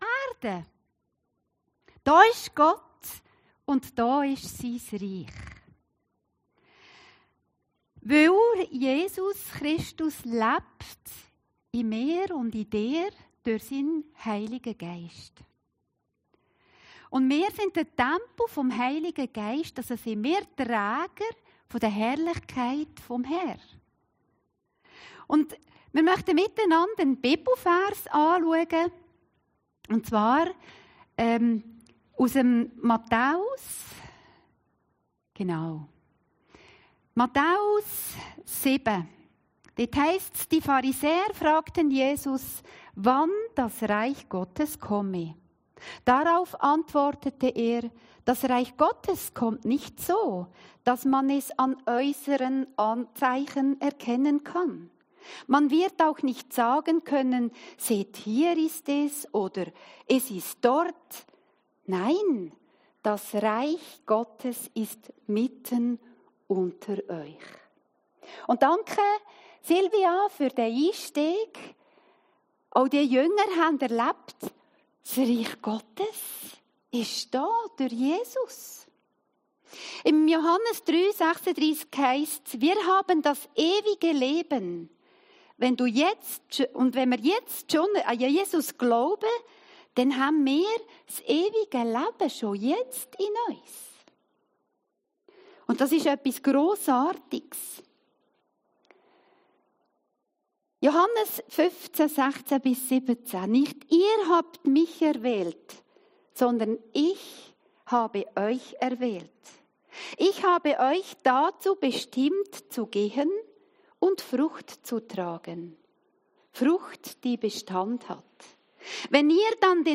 Predigten Heilsarmee Aargau Süd – Mehr Himmel auf Erden